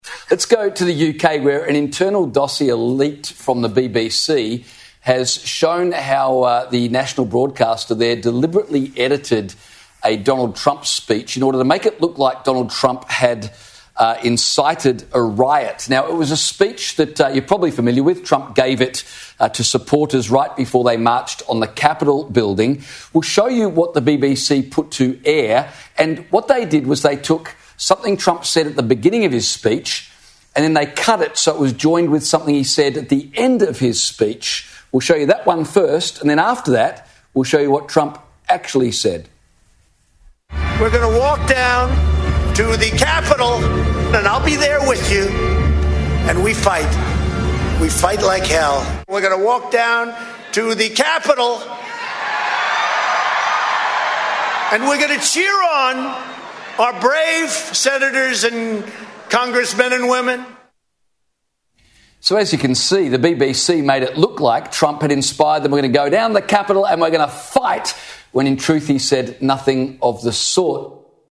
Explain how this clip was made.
Here are the two clips, taken from the Sky News Australia broadcast on 4 Nov 2025: